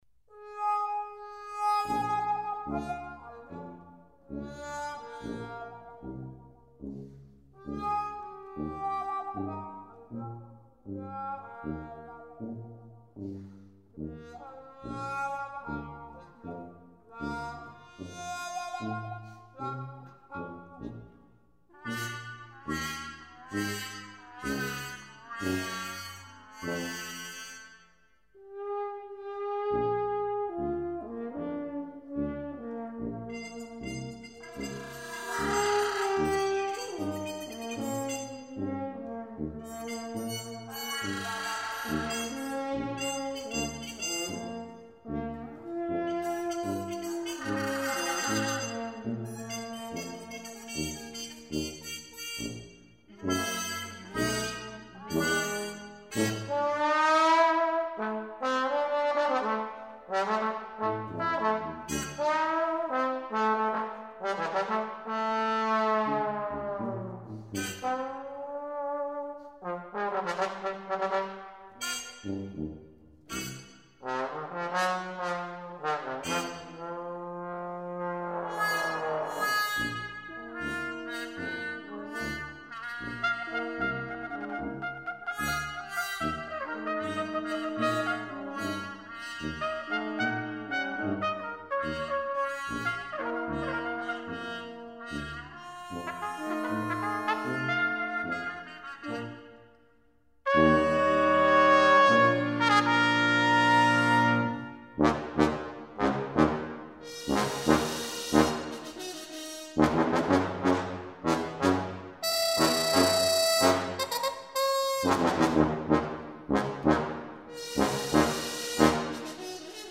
Instrument: brass quintet (score and parts)